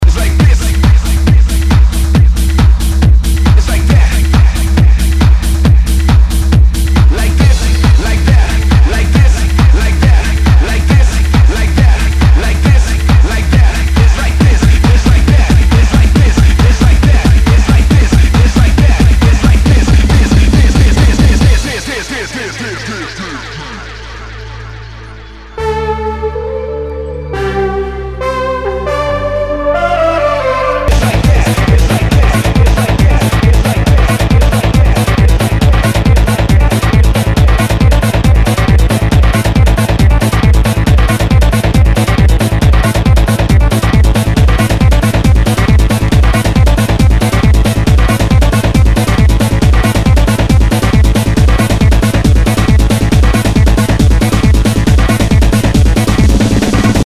HOUSE/TECHNO/ELECTRO
ナイス！ハード・テクノ！